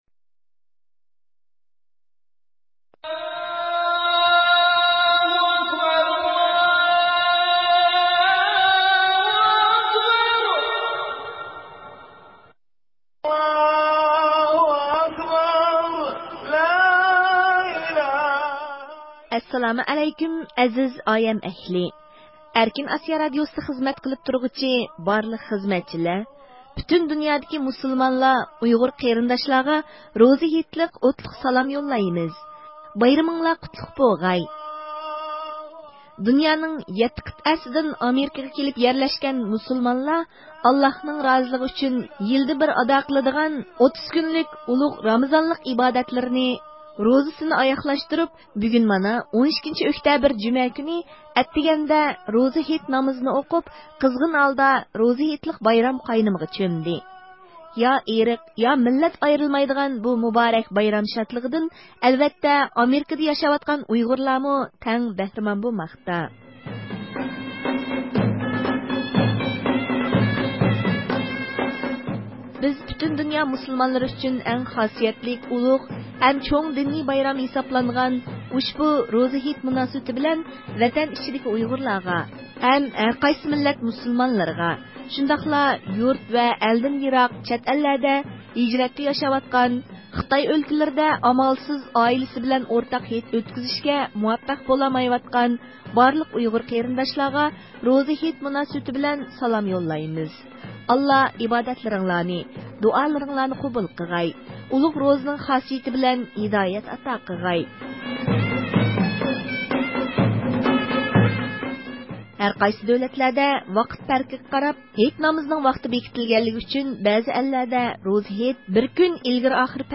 مۇشۇ خوشاللىق ھەم خاسىيەتلىك مىنۇتلاردا ئۆز ۋەتىنى ئۇيغۇر ئېلى ، ئۇيغۇر خەلقى ھەم پەرزەنتلىرىنى چوڭقۇر سېغىنغان ئۇيغۇر مىللىي ھەرىكىتىنىڭ رەھبىرى رابىيە قادىر ئانا ، ھېيت كۈنى ئەتتىگەندە تېلېفون زىيارىتىمىزنى قوبۇل قىلىپ ، يۈرەك سۆزلىرىنى ئىپادە قىلدى.